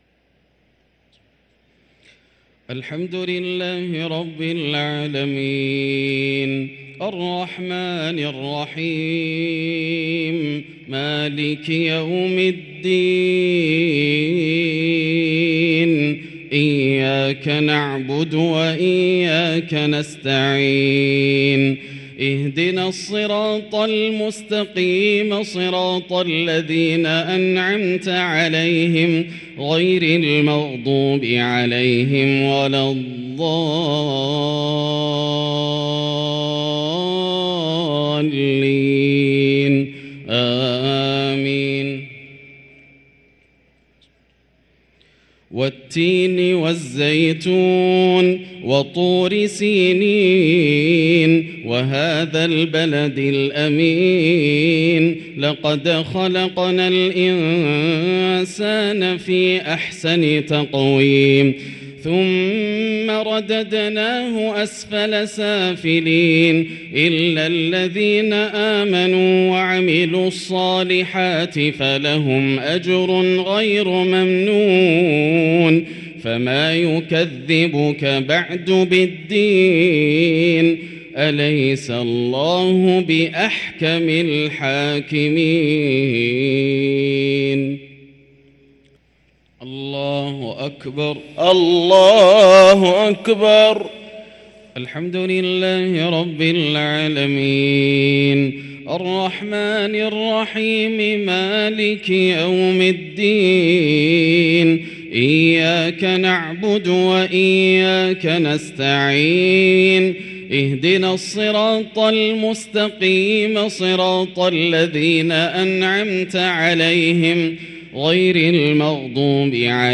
صلاة العشاء للقارئ ياسر الدوسري 12 رمضان 1444 هـ
تِلَاوَات الْحَرَمَيْن .